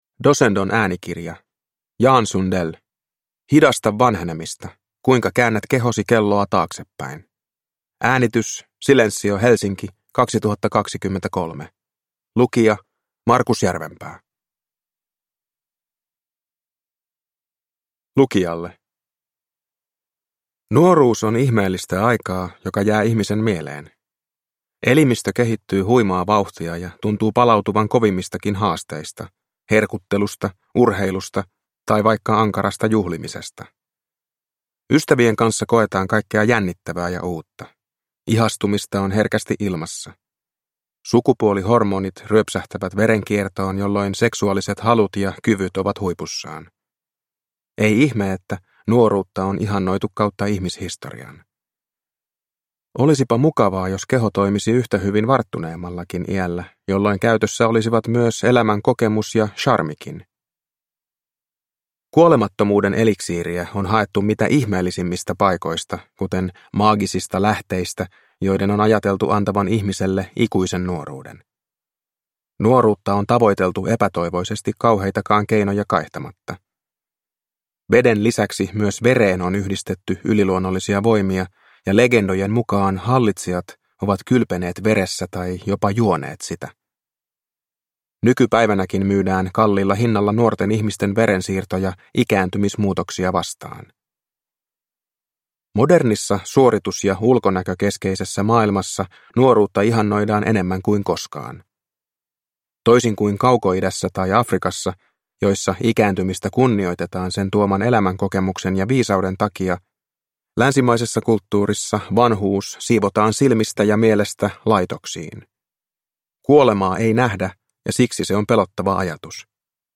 Hidasta vanhenemista – Ljudbok – Laddas ner